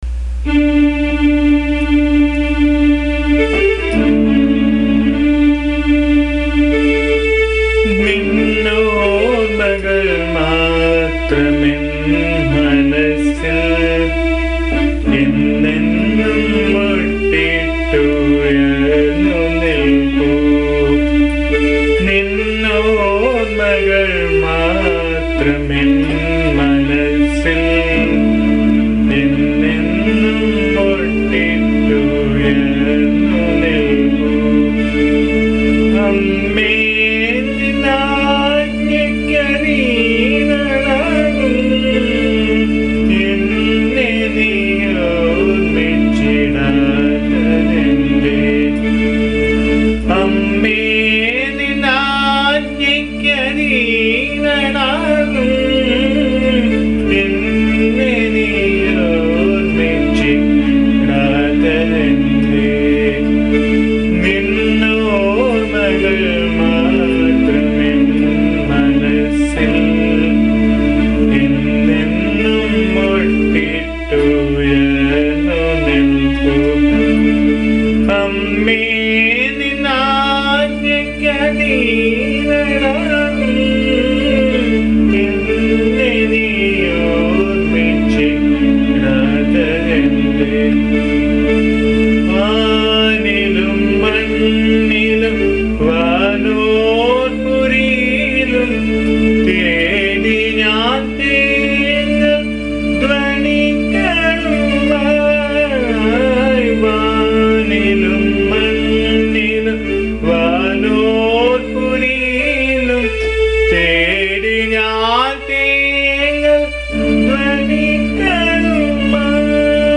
This is a very beautiful song set in Raga Brindavani Sarang.